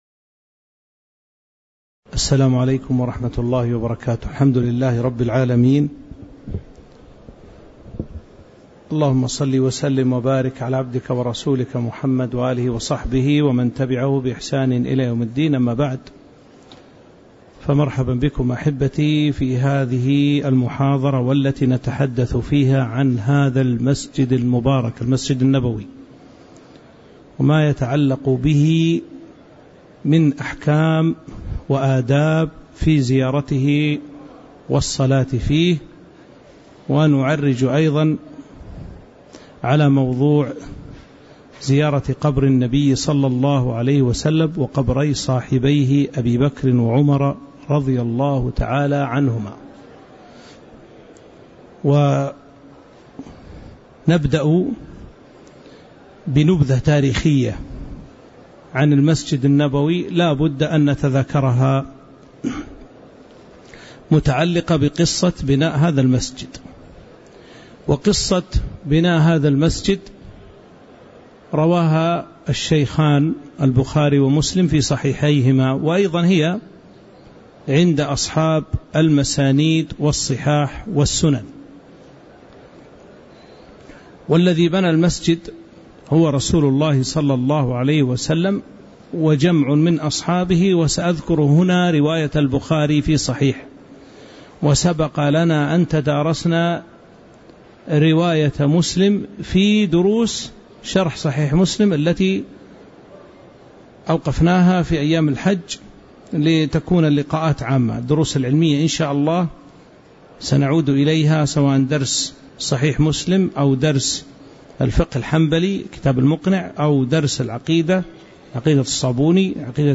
تاريخ النشر ٢٤ ذو القعدة ١٤٤٥ هـ المكان: المسجد النبوي الشيخ